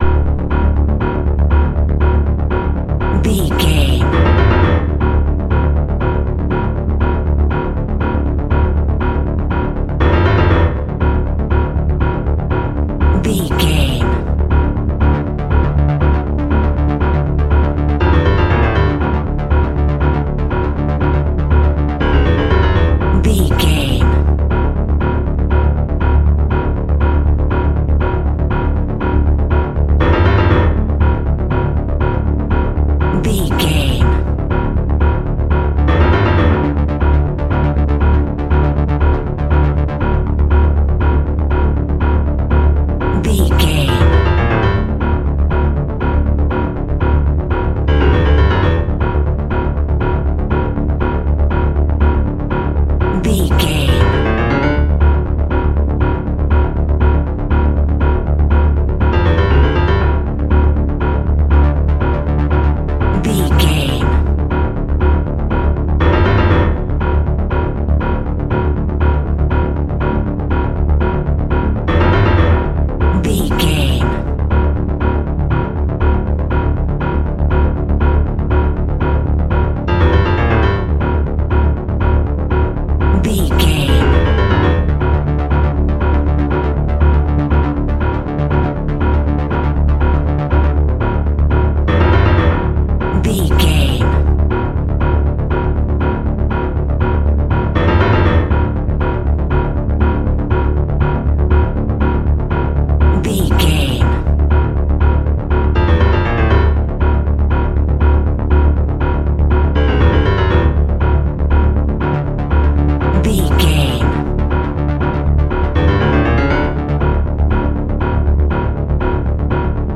Aeolian/Minor
D
Fast
tension
ominous
suspense
dramatic
haunting
eerie
piano
synthesiser
ambience
pads